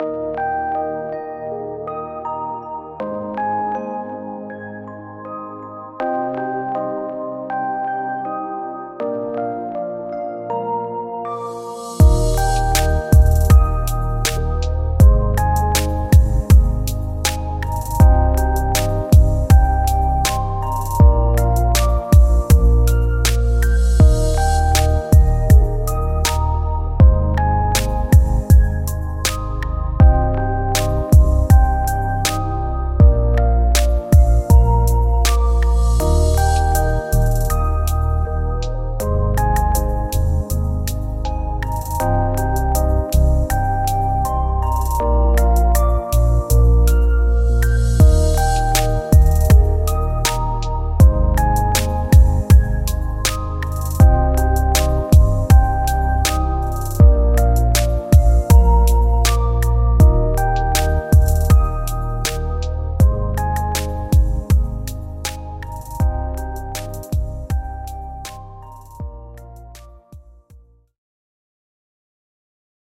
Genre: Lo-Fi